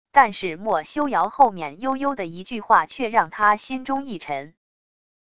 但是墨修尧后面悠悠的一句话却让他心中一沉wav音频生成系统WAV Audio Player